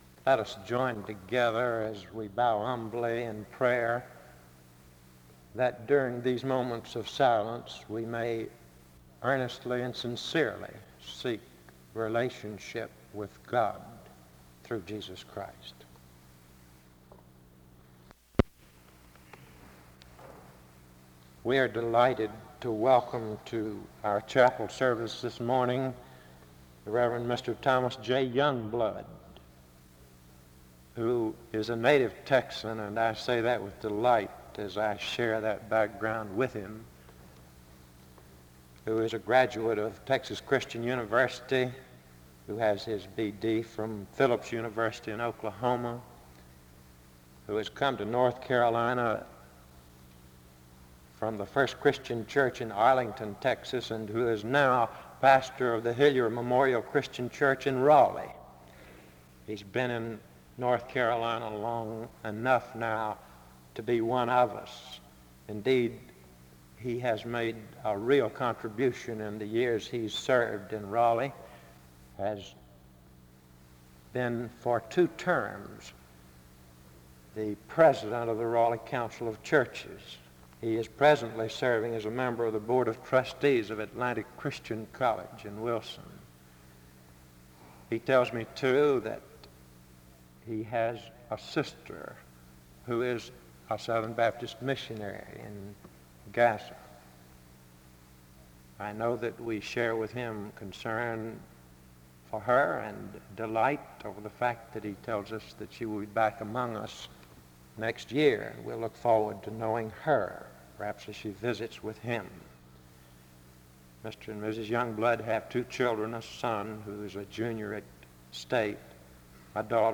The service begins with a word of prayer and an introduction to the speaker from 0:00-2:16.
SEBTS Chapel and Special Event Recordings SEBTS Chapel and Special Event Recordings